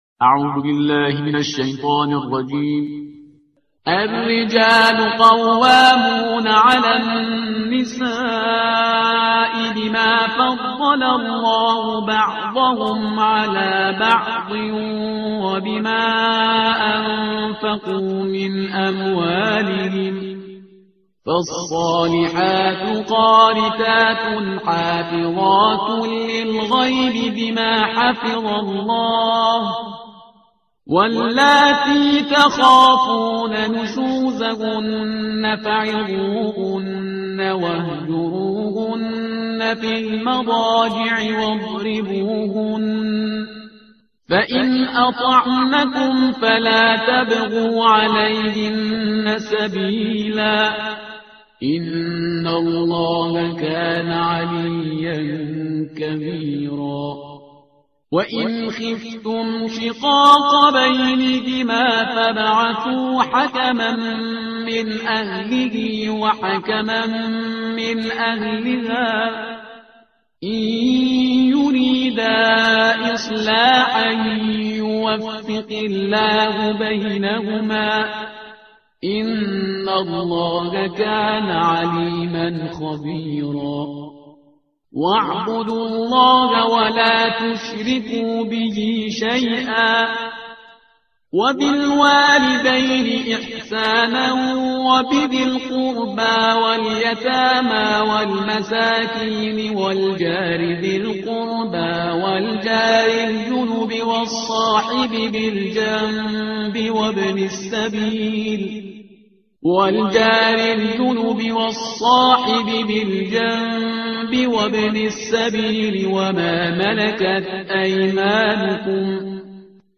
ترتیل صفحه 84 قرآن – جزء پنجم